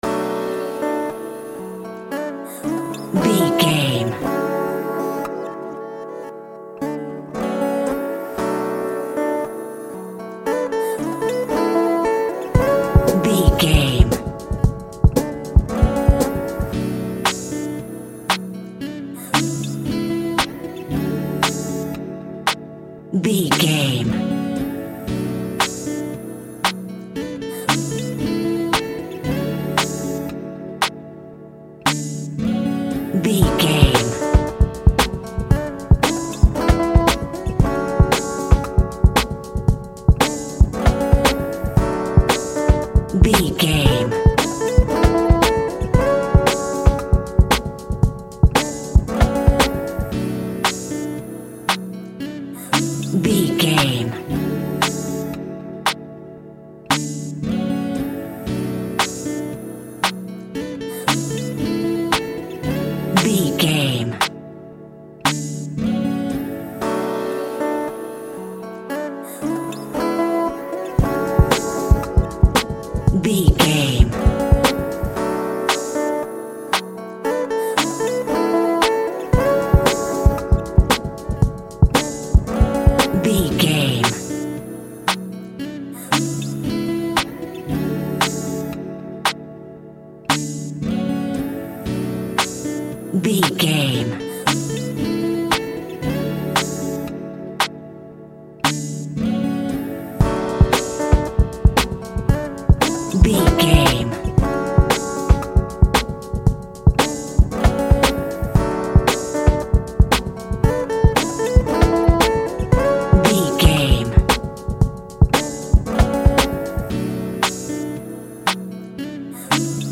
Ionian/Major
drums
dreamy
smooth
mellow
soothing
urban